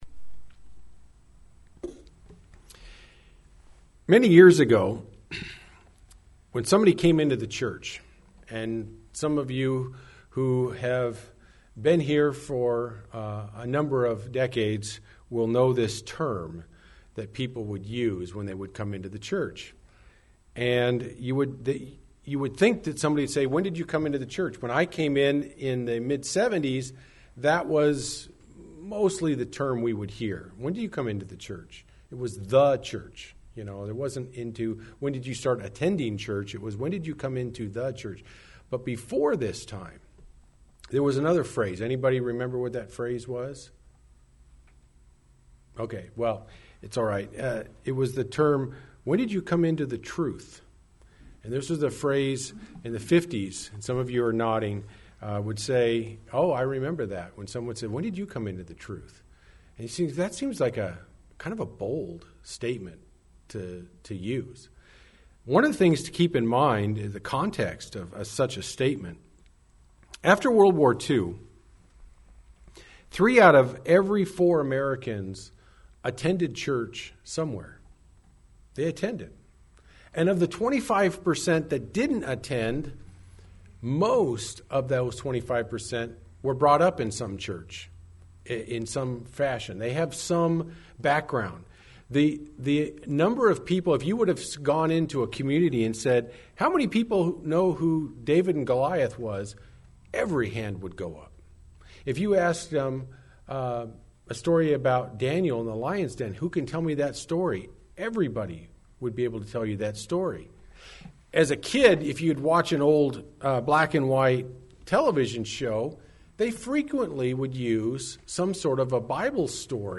Sermons
Given in Medford, OR